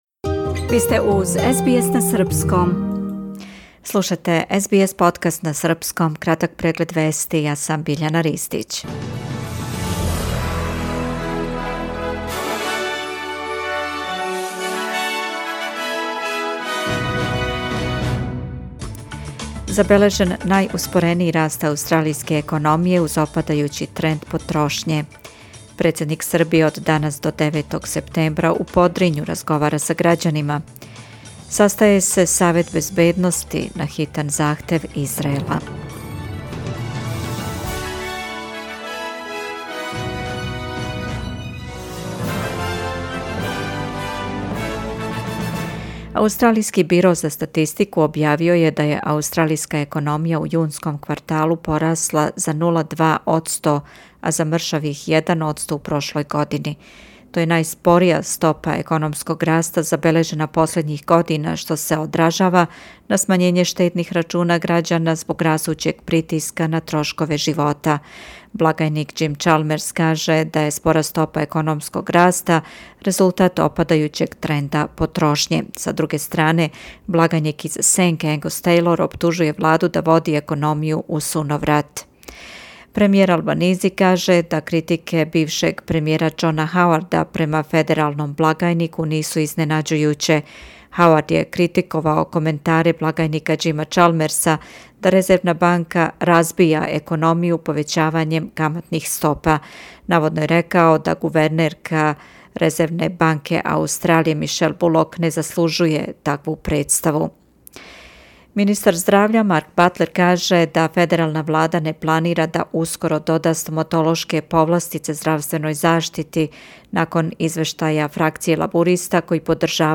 Преглед вести за 4. септембар 2024. године